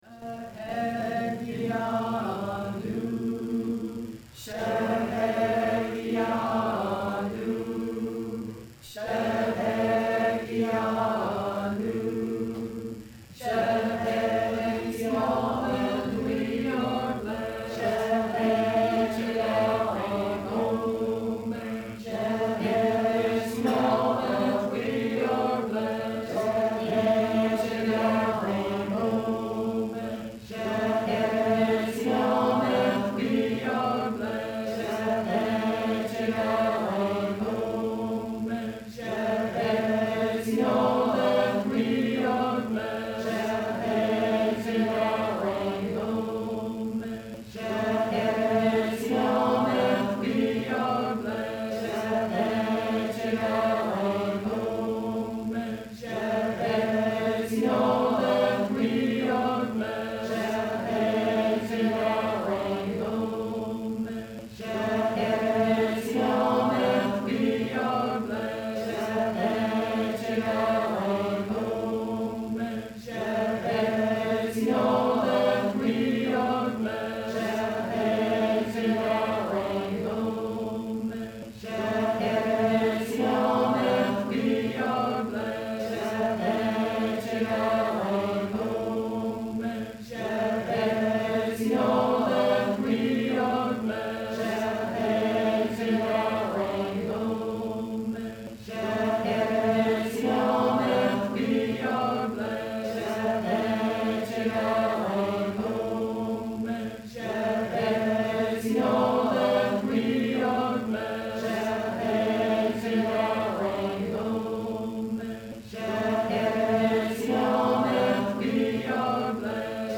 in several Hebrew chants during a Sunday morning worship service on February 17, 2008.*
*All chants were recorded live at Mill Valley Community Church, Mill Valley, California, February 17, 2008.